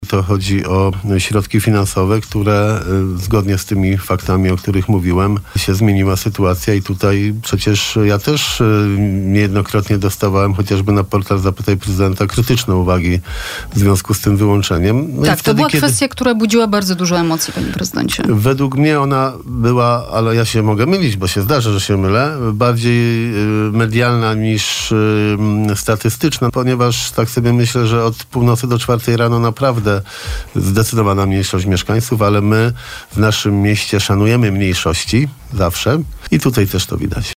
Tę informację w trakcie porannej rozmowy przekazał prezydent miasta.